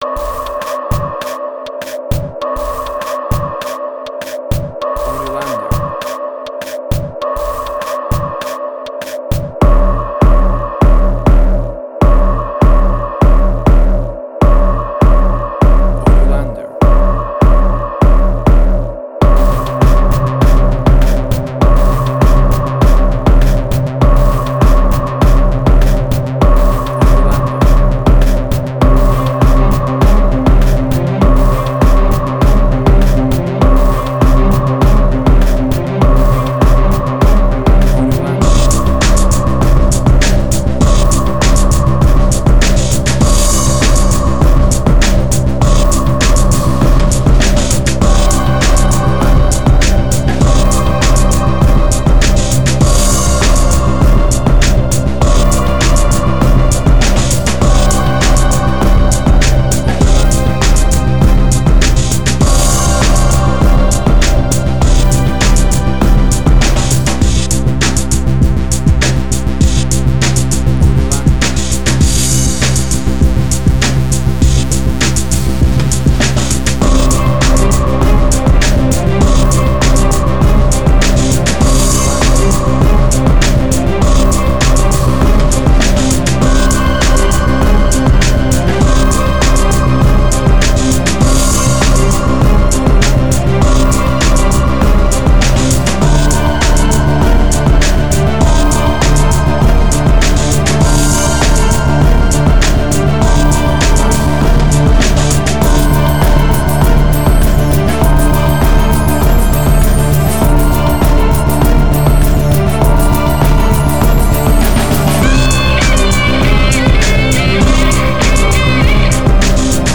Tempo (BPM): 100